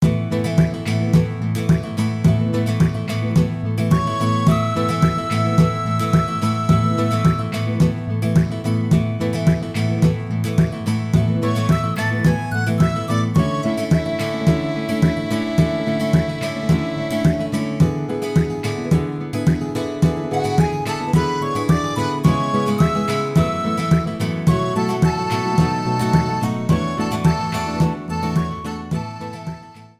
Trimmed file to 30 seconds, applied fadeout